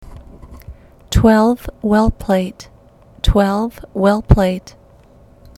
Pronunciation Guide
Click on a specific term to hear the English pronunciation.